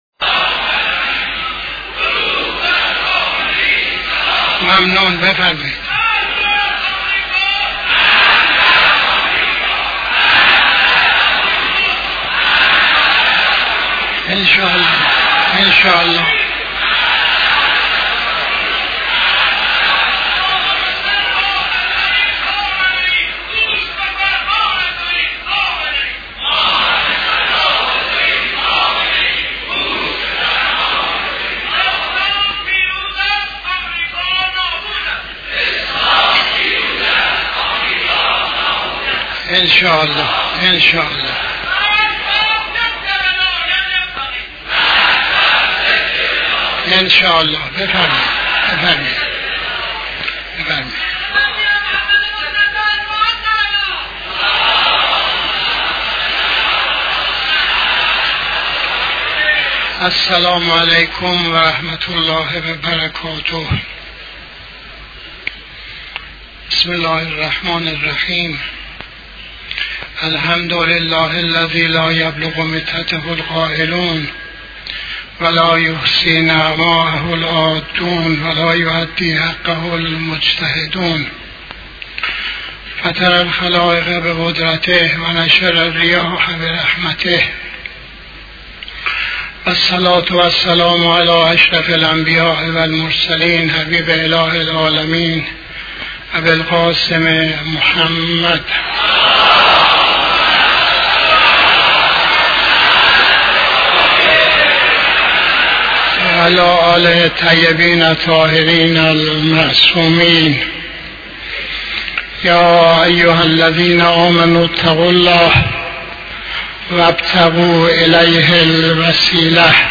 خطبه اول نماز جمعه 09-10-84